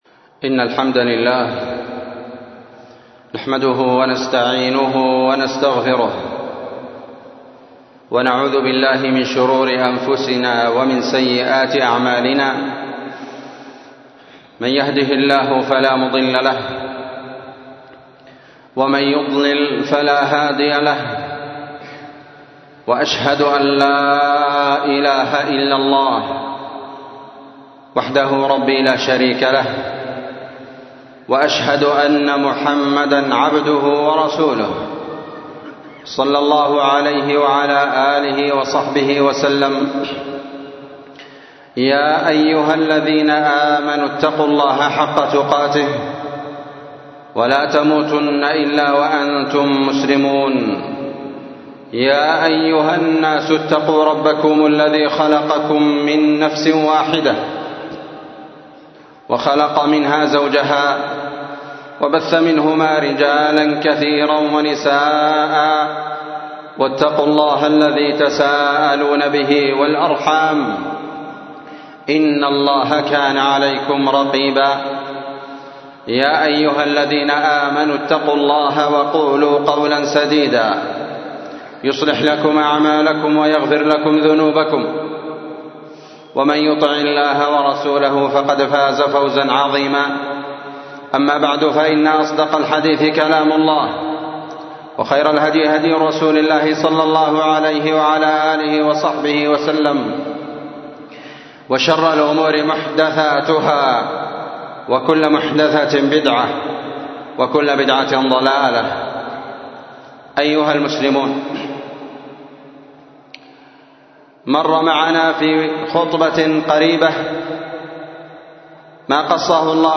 خطبة عبر من قصة أصحاب القرية في سورة ياسين 6شعبان 1445م